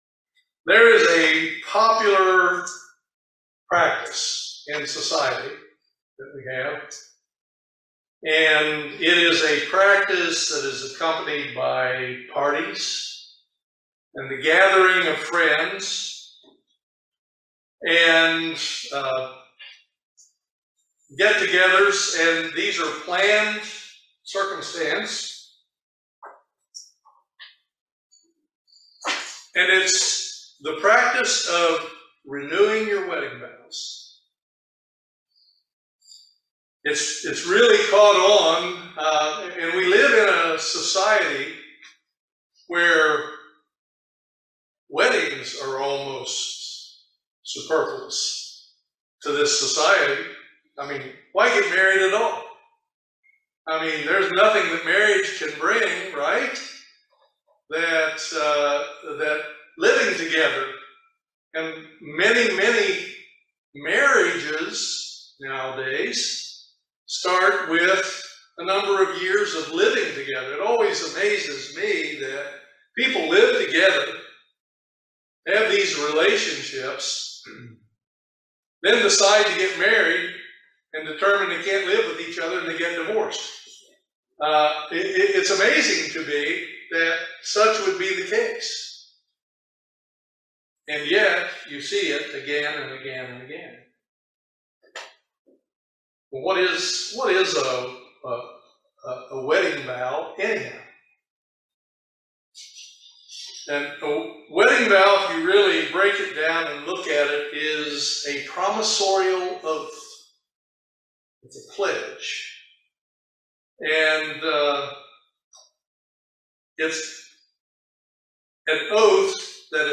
Join us for this excellent video sermon on the subject of renewing your "vows" at the Passover.